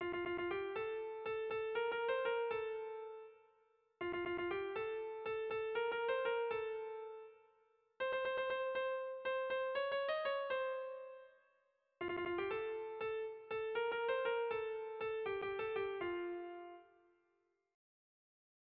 Tragikoa
Zortziko txikia (hg) / Lau puntuko txikia (ip)
A-A-B-B